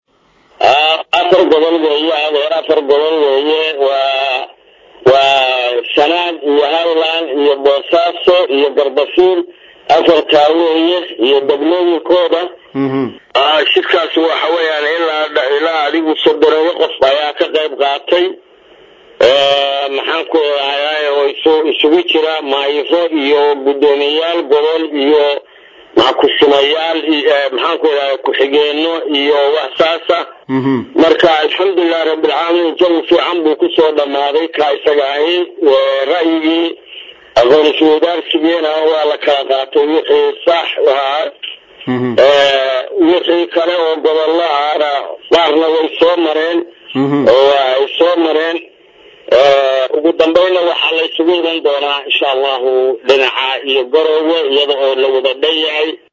Gudoomiyaha degmada martida loo yahay ee Laas qoray Mr. Yuusuf Jaamac Axmed, oo ka hadlay kulankaas, ayaa waxaa uu sheegay in ay tahay mid lagu doonayo aqoon iswaydaasiga wajigiisa koowaad lagu fuliyo, iyada oo kadib markii gobolada laga soo dhamaadana, madaxda gobolada iyo kuwa dagmooyinku ay isugu tagi doonaan, magaalo madaxda Puntland ee Garoowe.
Gudoomiyahan-Degmada-Laas-qoray-gobolka-Sanaag.mp3